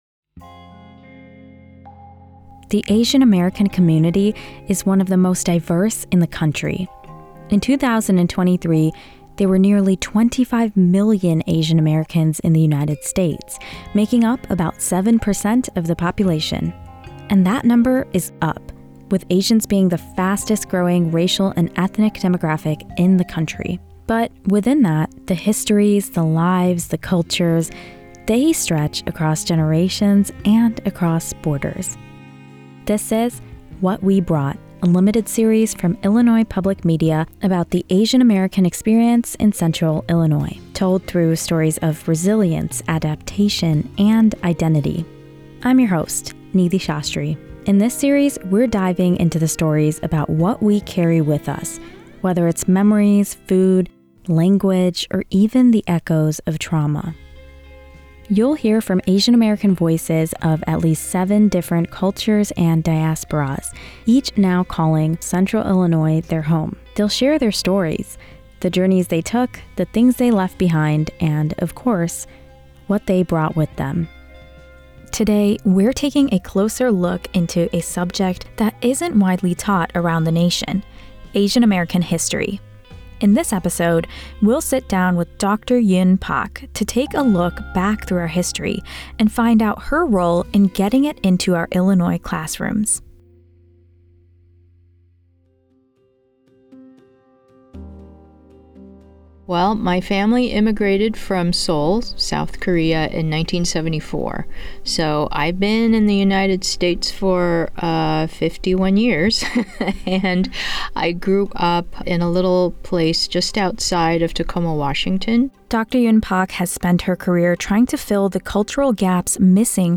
You’ll hear from Asian American voices of at least seven different cultures and diasporas, each now calling Central Illinois their home. They’ll share their stories — the journeys they took, the things they left behind, and, of course, what they brought with them.